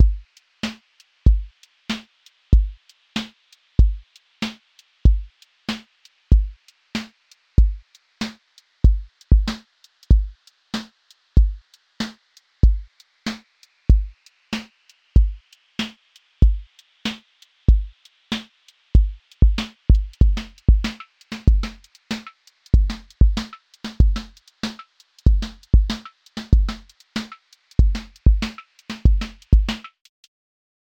Boom Bap Drums A
• voice_kick_808
• voice_snare_boom_bap
• voice_hat_rimshot
• tone_warm_body
• texture_vinyl_hiss
Boom-bap pocket with restrained texture release for tier2 retry verification